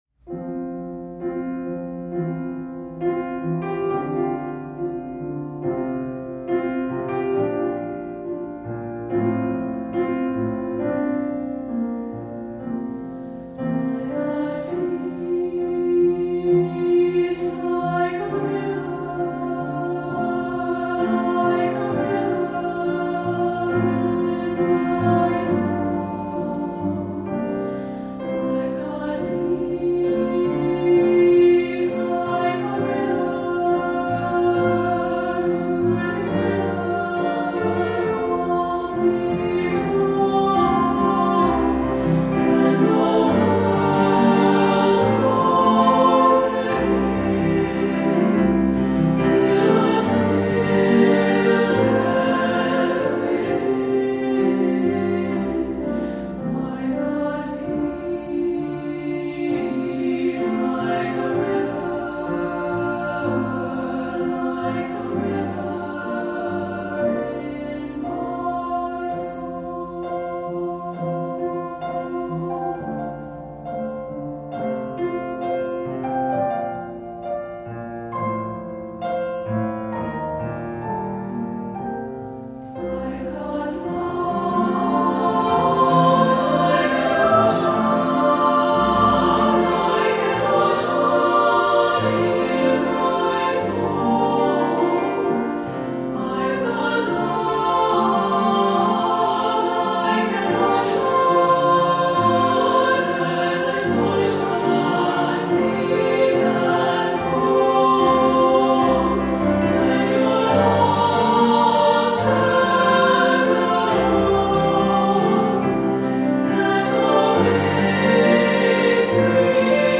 Voicing: SSAA and Piano